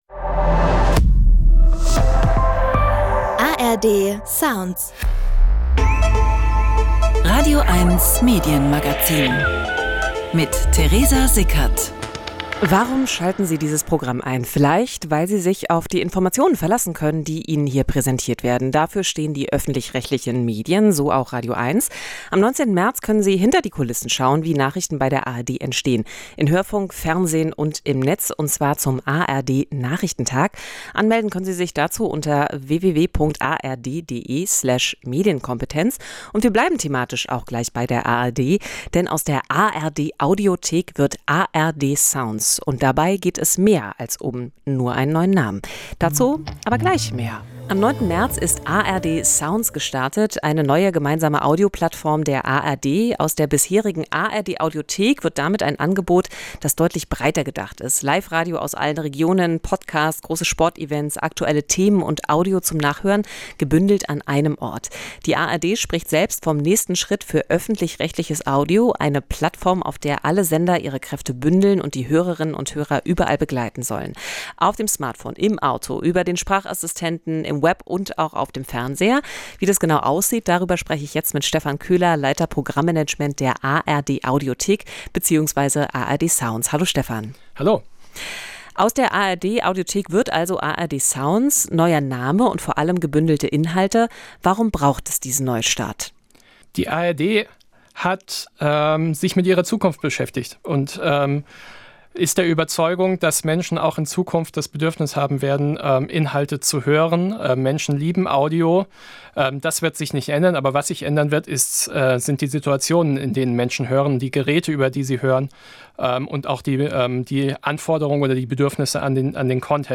Im Faktencheck geht es um Influencer in Dubai: Nach Raketenangriffen in der Golfregion verbreiten einige Social-Media-Accounts ein Bild von völliger Normalität.